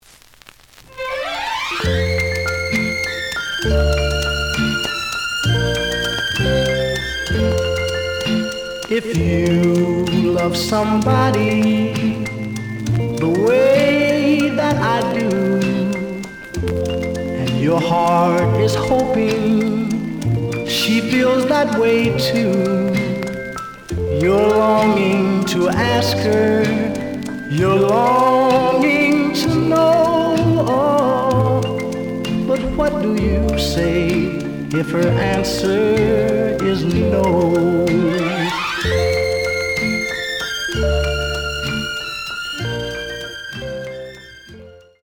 The audio sample is recorded from the actual item.
●Genre: Rhythm And Blues / Rock 'n' Roll
Some click noise on later half of A side due to scratches.)